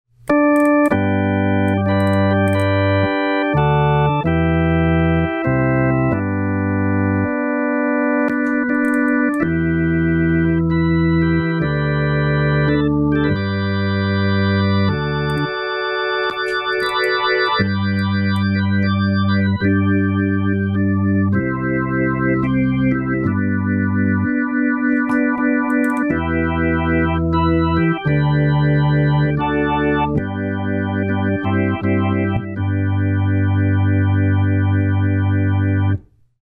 One of my first experiments is putting the Dual-Phase on a dry Hammand organ sound.
hastily thrown together demo — put up the Roland R-07 and hit RECORD. You’ll hear me hit the pedal switches after each line:
• First, totally dry organ.
• Next, Phasor A alone.
• Next, both Phasor A and Phasor B.
• Finally, Phasor B alone.
The decrease in volume at the very end is due to the R-07 gain control kicking in.
Behringer_DualPhase.mp3